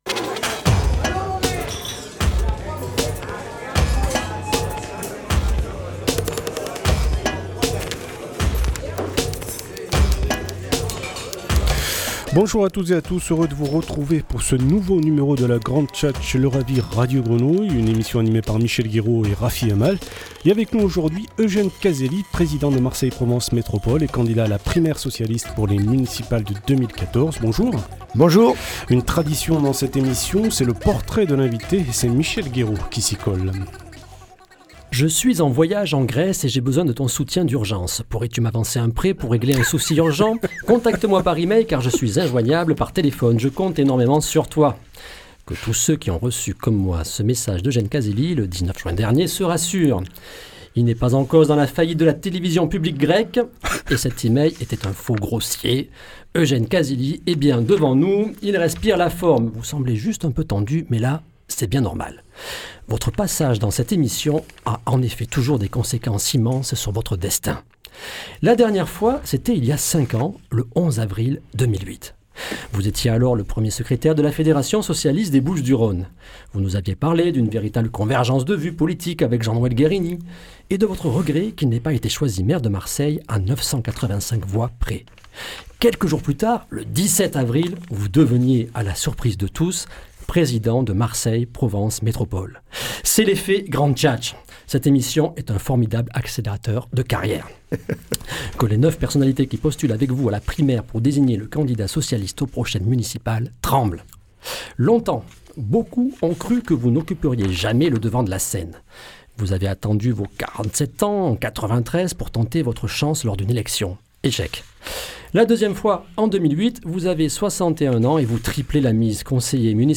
Eugène Caselli, président de MPM, candidat à la primaire PS marseillaise, invité de la Grande Tchatche
Entretien radio en partenariat avec Radio Grenouille